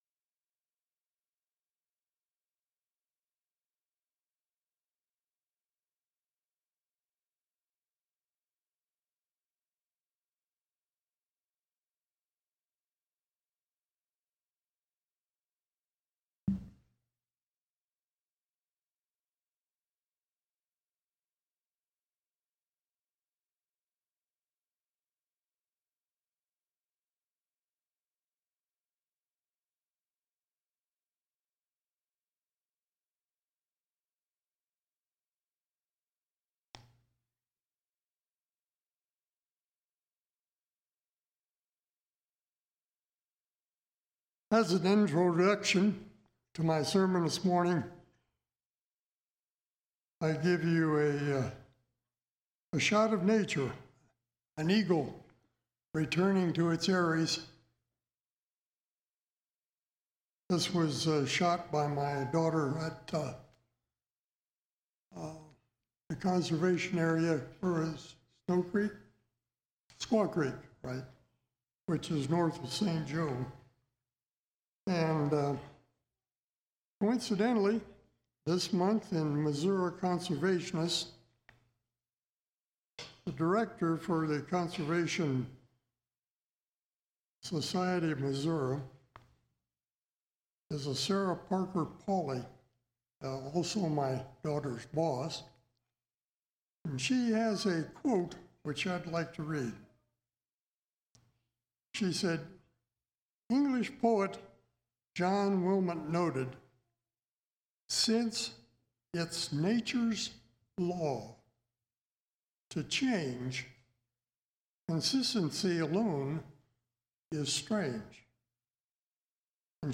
5/28/2023 Location: Temple Lot Local Event